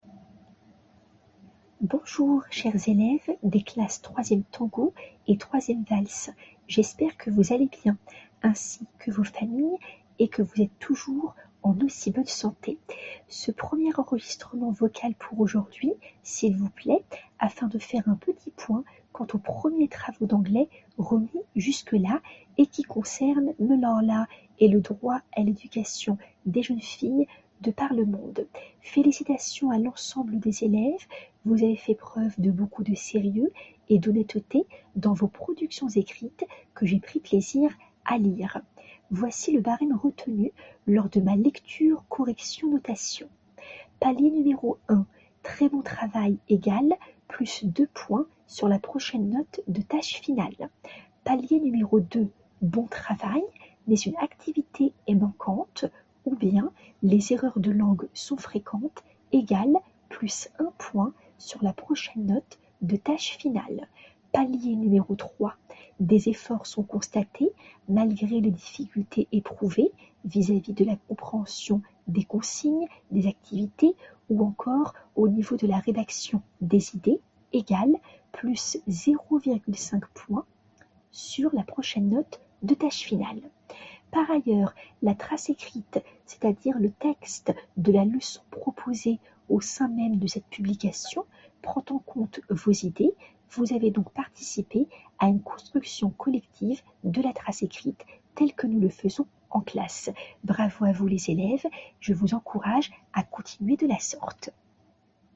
Audio 1 du professeur d'une durée de 01:45: